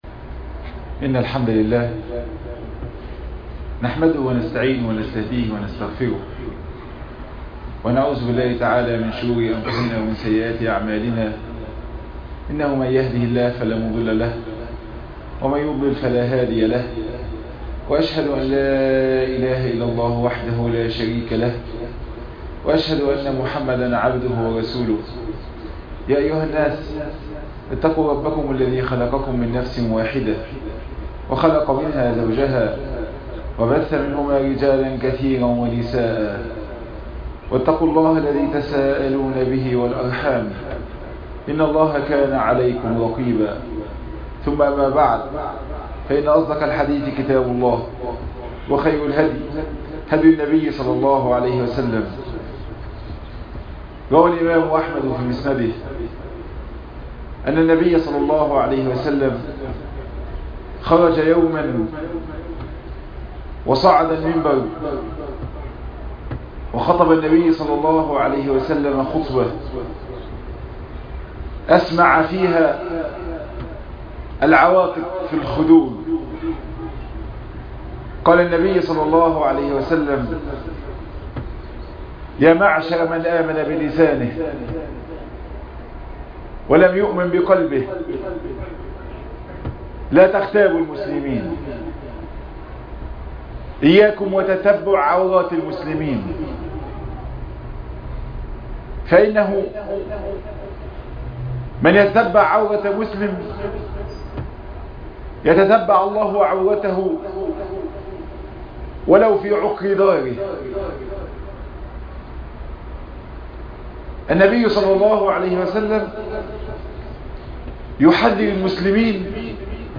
تفاصيل المادة عنوان المادة الغيبة - خطب الجمعة تاريخ التحميل السبت 11 يوليو 2020 مـ حجم المادة 17.98 ميجا بايت عدد الزيارات 244 زيارة عدد مرات الحفظ 125 مرة إستماع المادة حفظ المادة اضف تعليقك أرسل لصديق